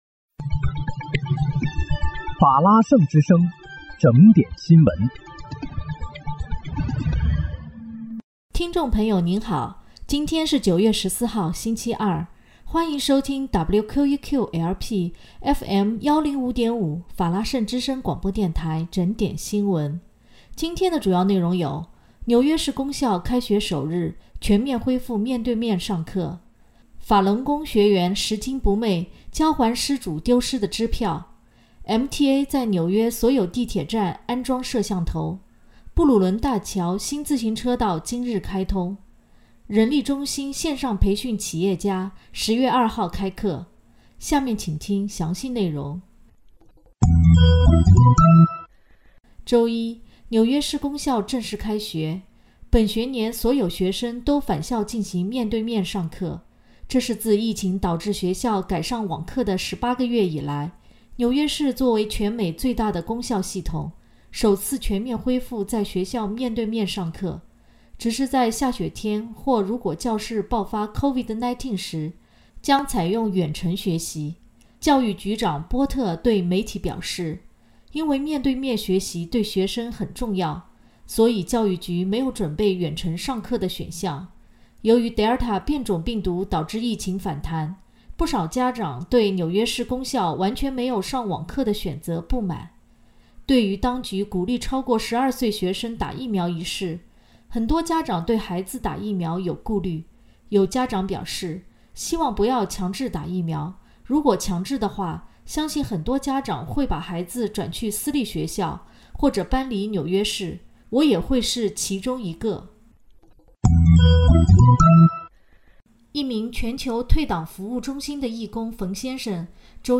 9月14日（星期二）纽约整点新闻
听众朋友您好！今天是9月14号，星期二，欢迎收听WQEQ-LP FM105.5法拉盛之声广播电台整点新闻。